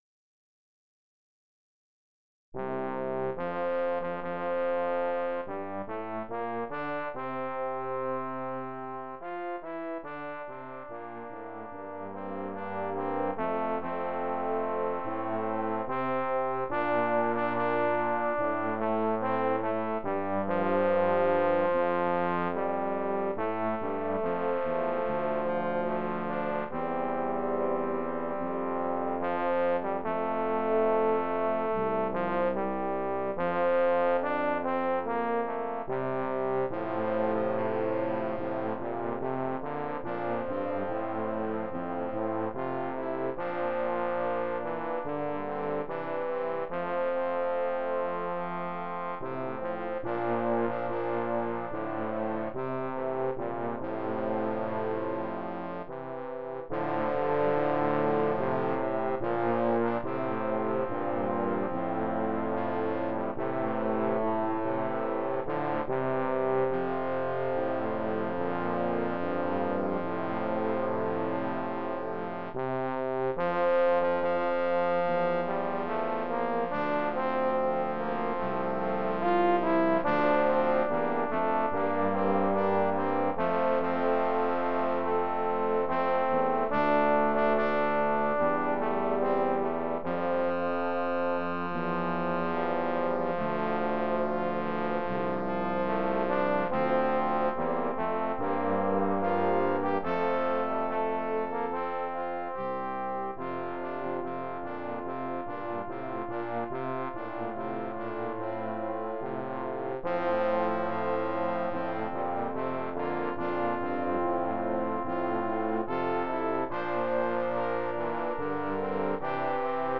MIDI
A mashup of several versions of the traditional American folk tune.